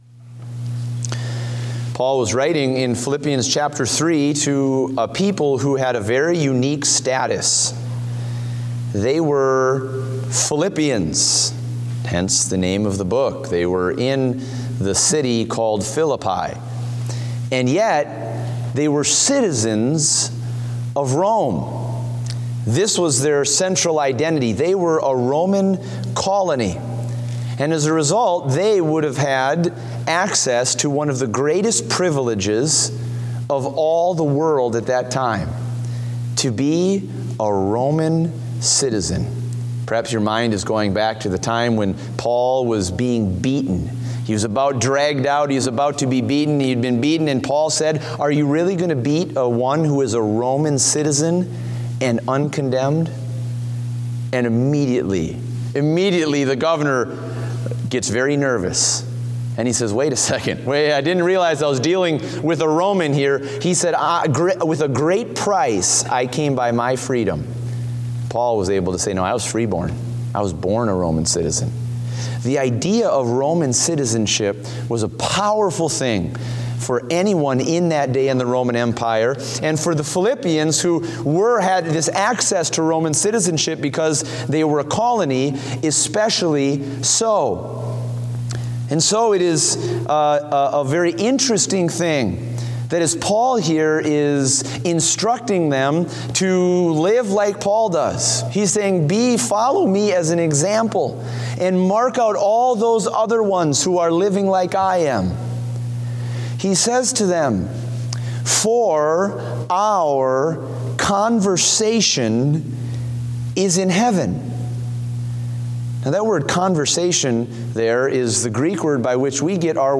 Date: December 11, 2016 (Evening Service)